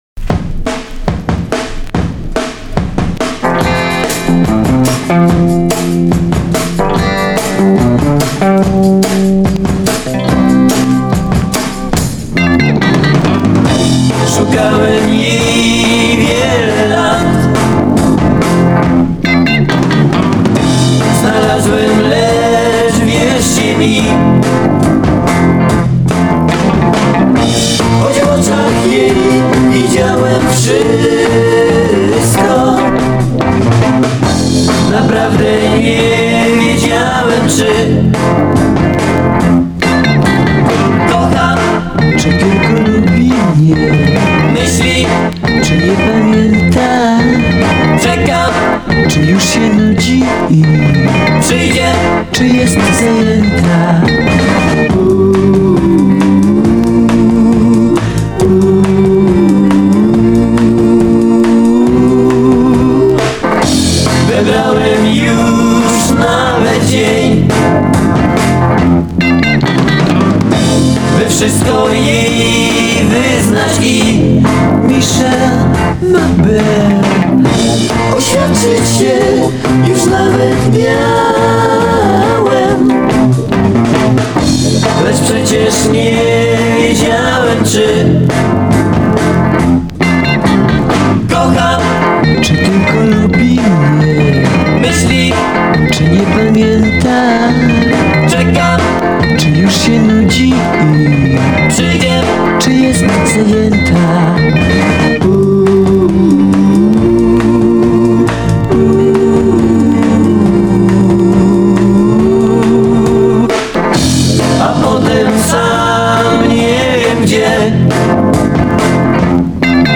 Пионеры польского биг-битa.